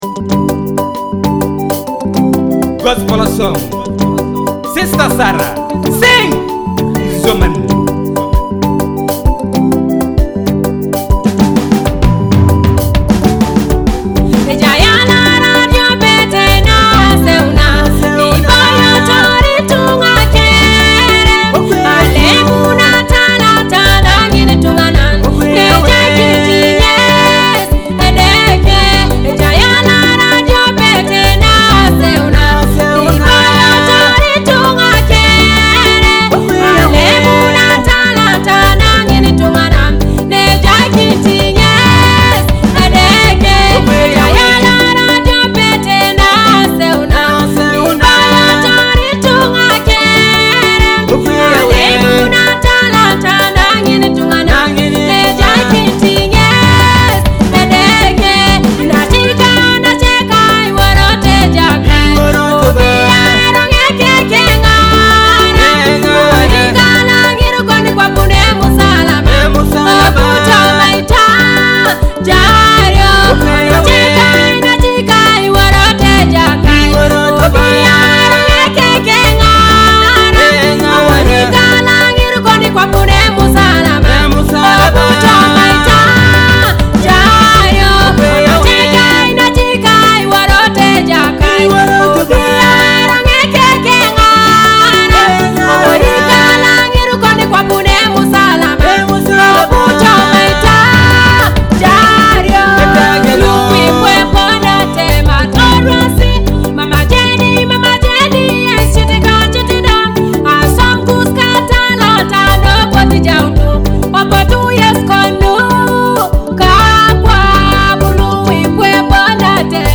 Soul-stirring